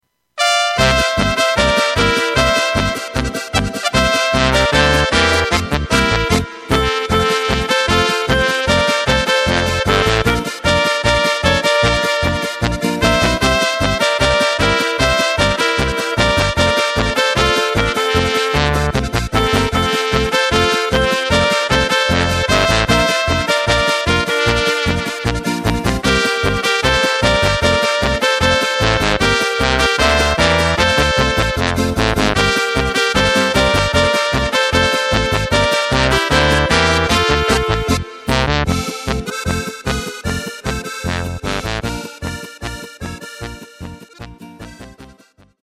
Takt:          2/4
Tempo:         152.00
Tonart:            C
Flotte Polka aus dem Jahr 2018!
Playback mp3 mit Lyrics